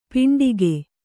♪ piṇḍige